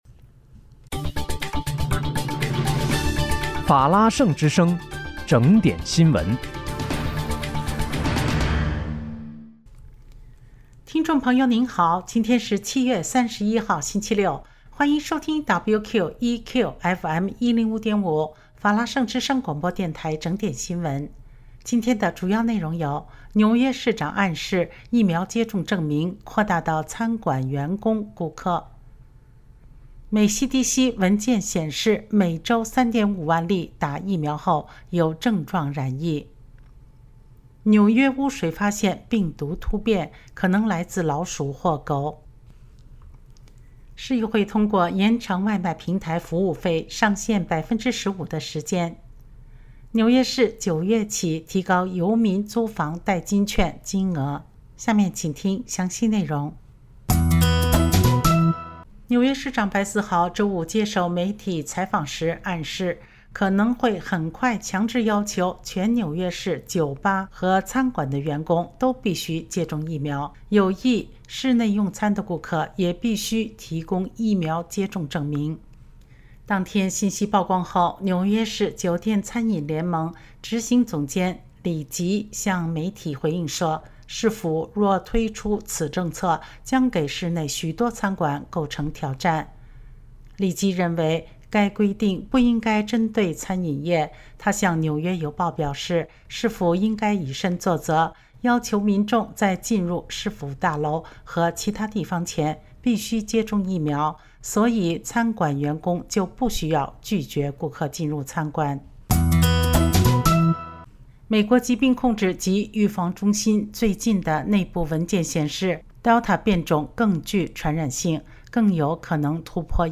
7月31日（星期六）纽约整点新闻